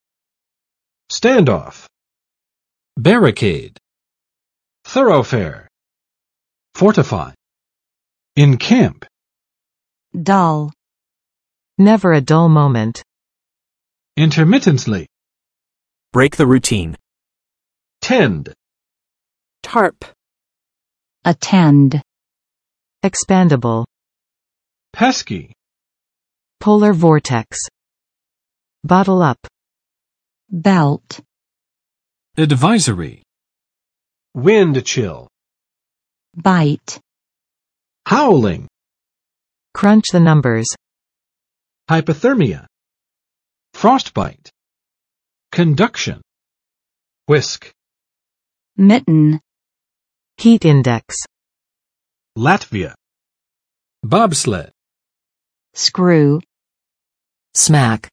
[ˋstænd͵ɔf] n. 【美】僵持